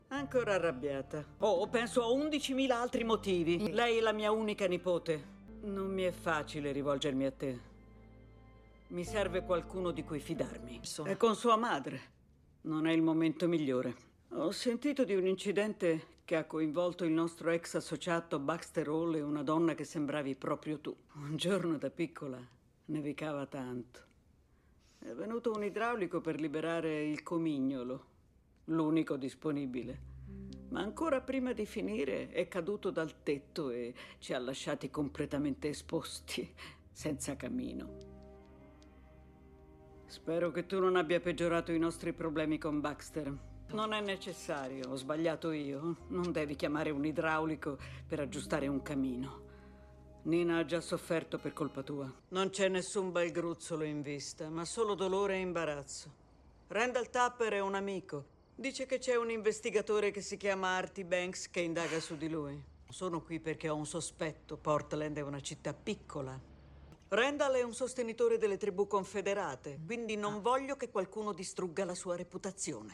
voce di Ludovica Modugno nel telefilm "Stumptown", in cui doppia Tantoo Cardinal.